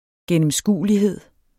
Udtale [ gεnəmˈsguˀəliˌheðˀ ]